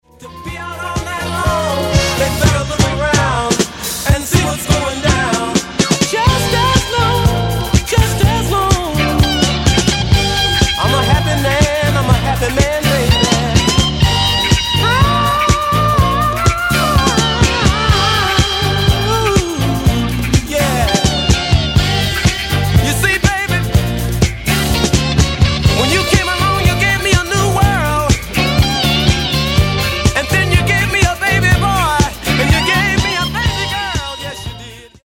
Genere:   Disco | Funky | Soul